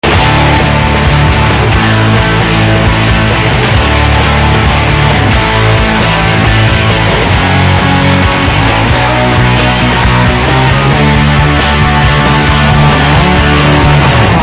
Kind of hard to hear in the beginning of the clip